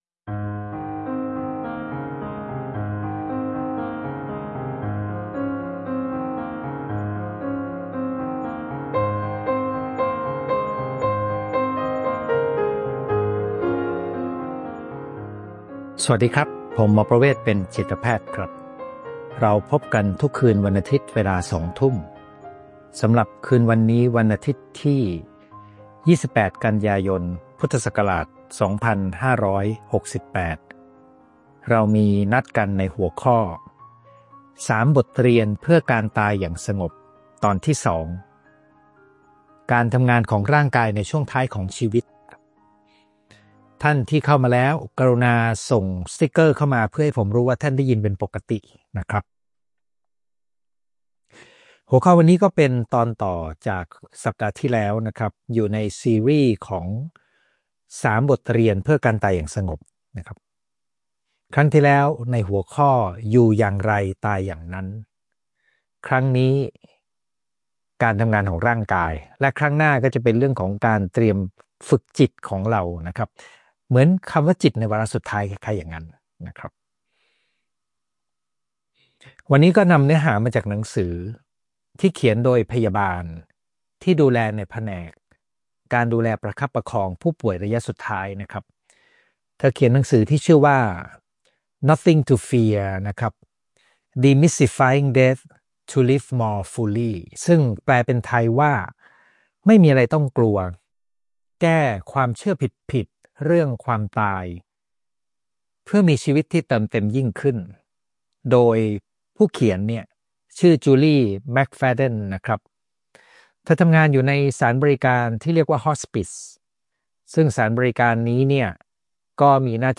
ไลฟ์ประจำวันอาทิตย์ที่ 28 กันยายน 2568 เวลาสองทุ่ม